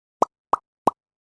На этой странице собраны звуки кнопок меню — короткие и четкие аудиофрагменты, подходящие для приложений, сайтов и игр.
Поп-поп-поп